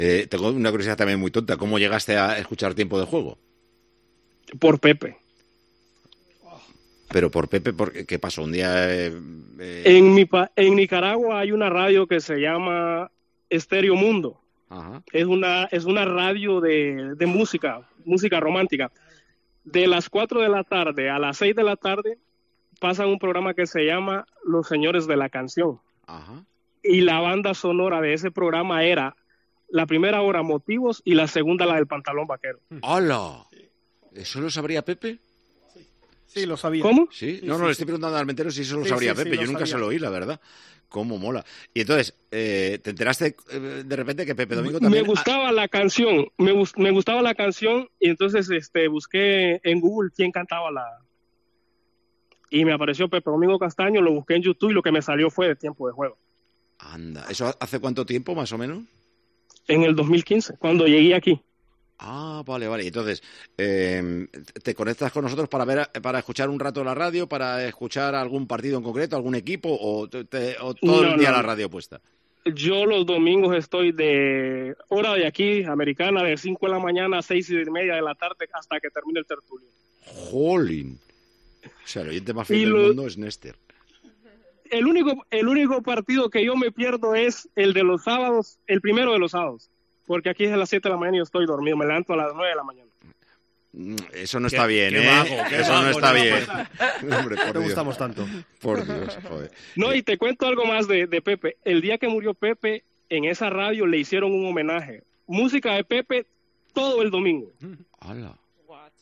Fragment d'una trucada telefònica d'un oient nicaragüenc que escolta el programa des d'Estats Units i recorda a Pepe Domingo Castaño.
Esportiu